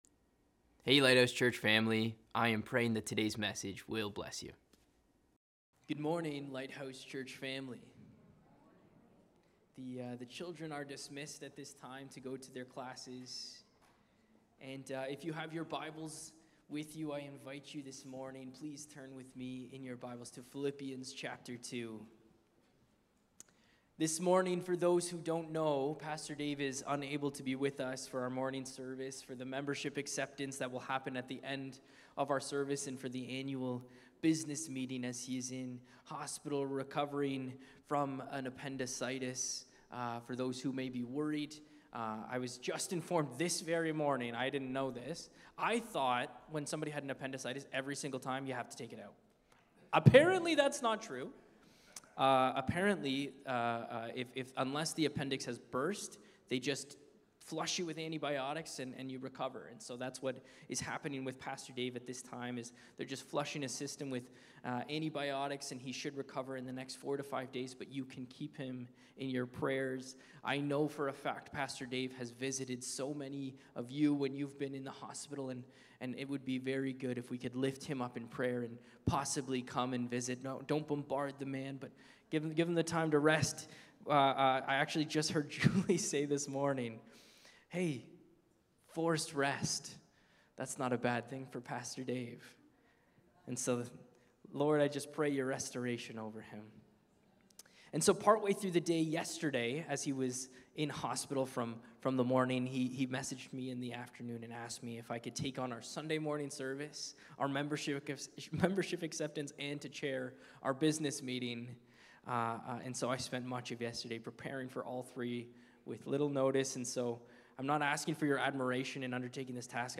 Lighthouse Niagara Sermons